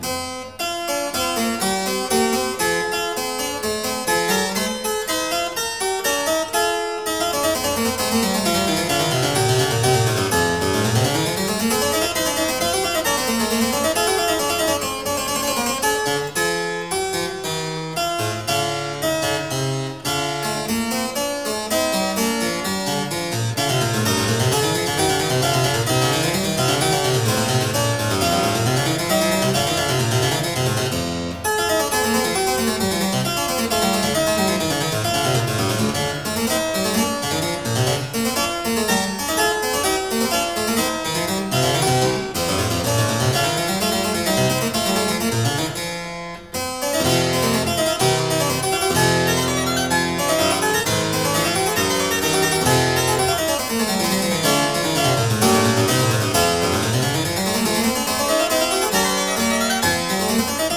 史威林克 / 大鍵琴作品
具有簡潔明朗的旋律美感，而他在教育上的努力也影響了德國巴洛可音樂的發展。
而這也讓她在演奏這些作品時能深入到大鍵琴機能的內在，重現巴洛可的活潑精神。